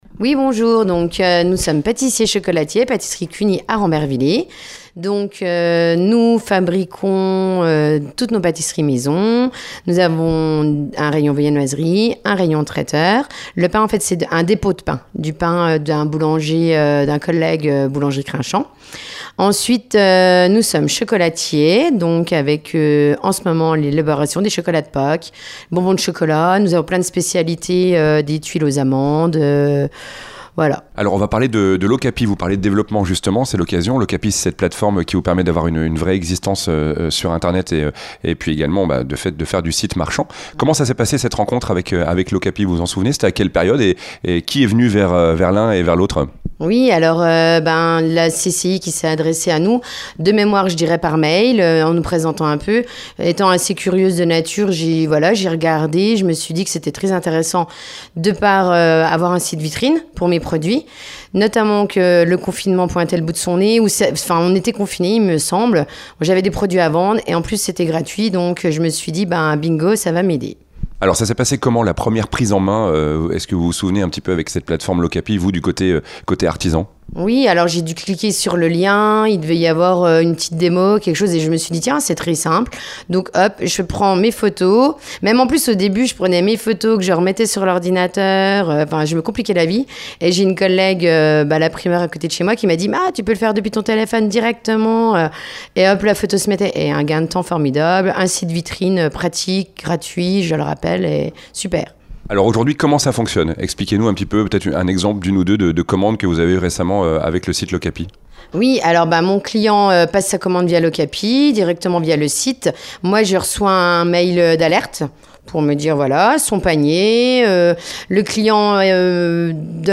Interview Vosges FM